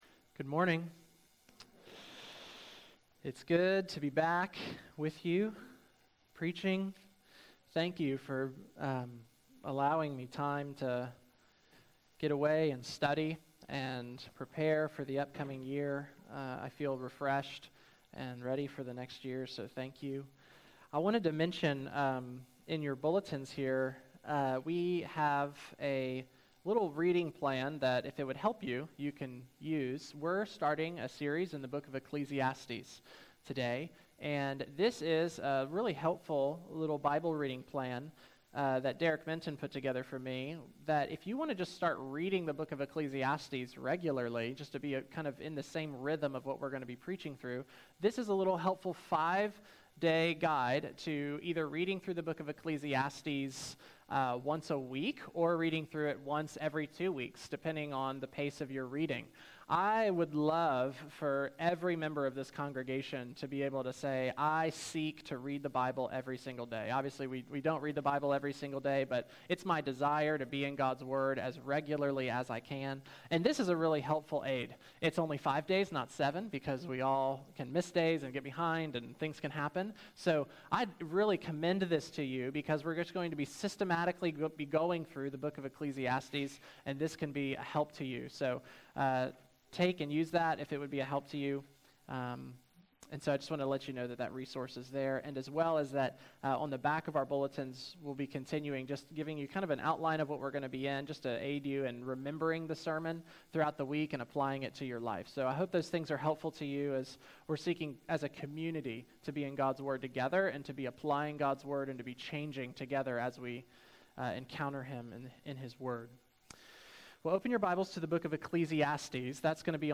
Service Morning Worship